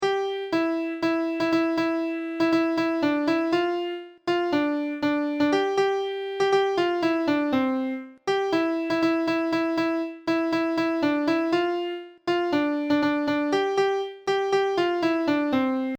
being sung